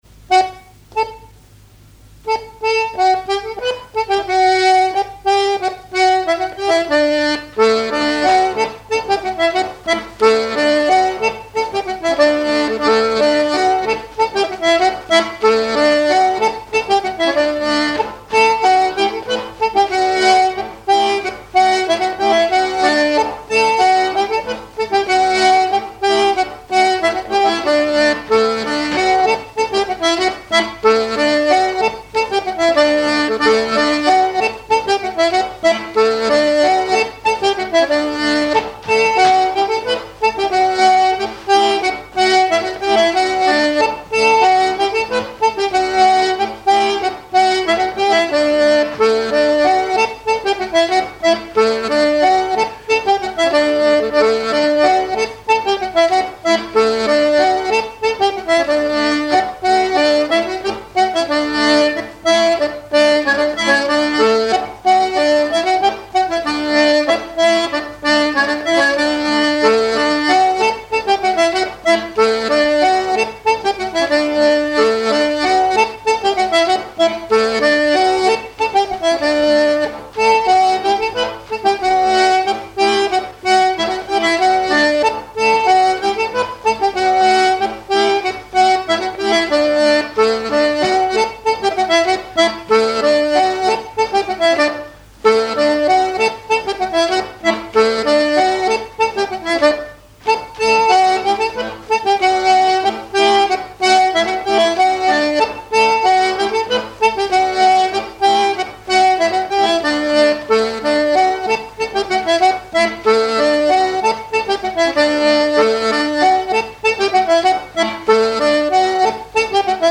pas d'été
airs de danse à l'accordéon diatonique
Pièce musicale inédite